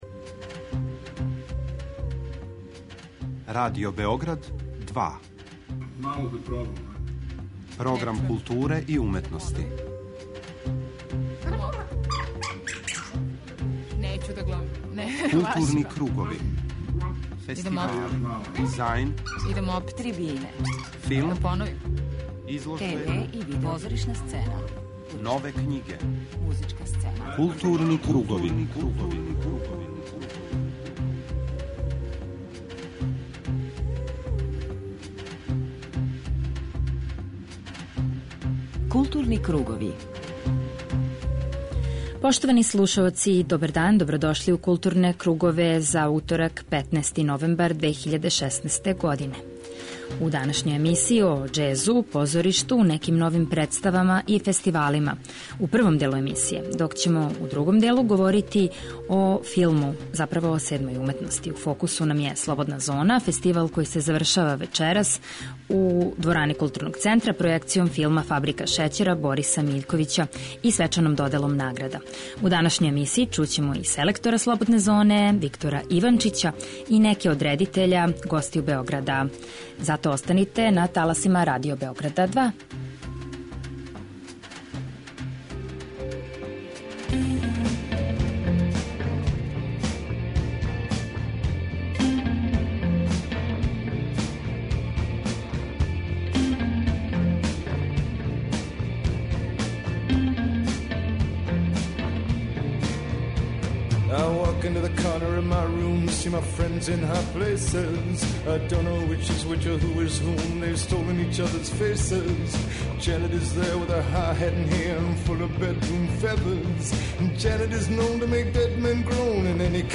преузми : 40.89 MB Културни кругови Autor: Група аутора Централна културно-уметничка емисија Радио Београда 2.